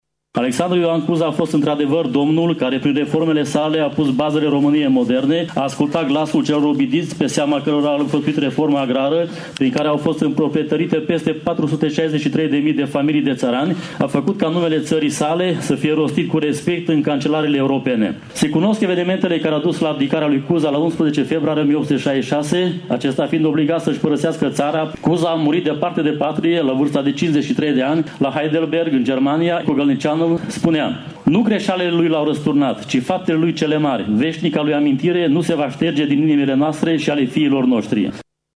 Împlinirea a 156 de ani de la Unirea Principatelor Române a fost sărbătorită astăzi la Tîrgu-Mureş în curtea interioară a Inspectoratului Judeţean de Poliţie, unde au loc în fiecare an festivităţi de acest fel.